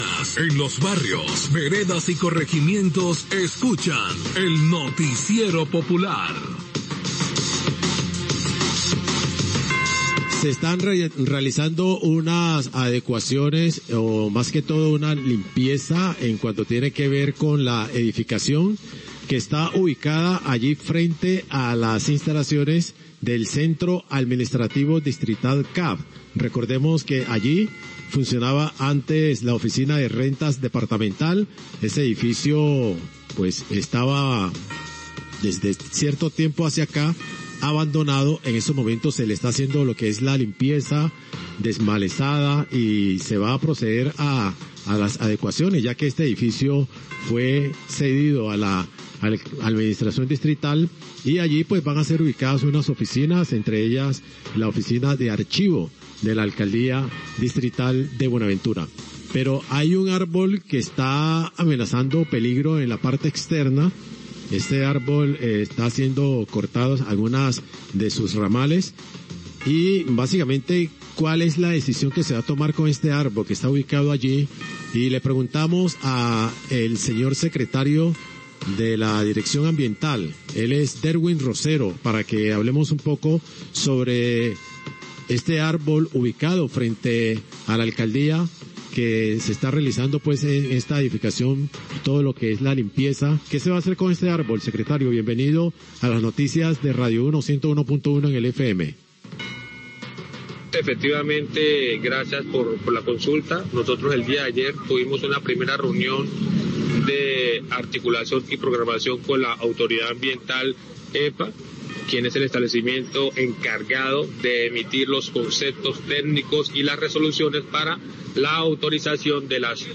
Trabajo de tala de árbol en la ciudad, Noticiero Radio Uno, 1229pm